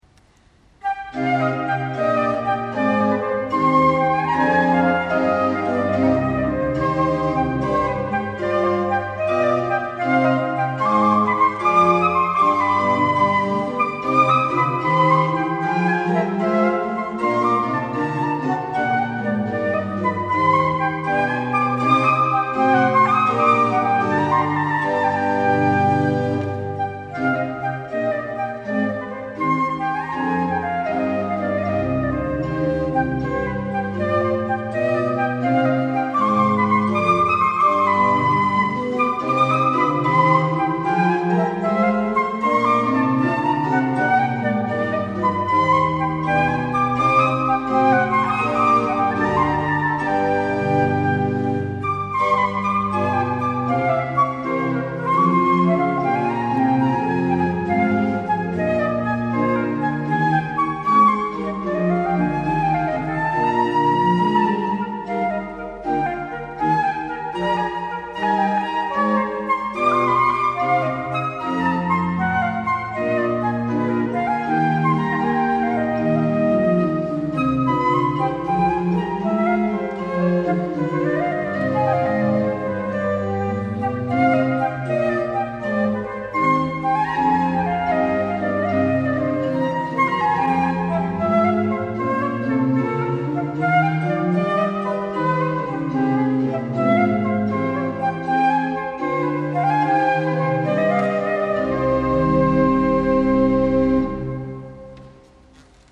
Flûte et orgue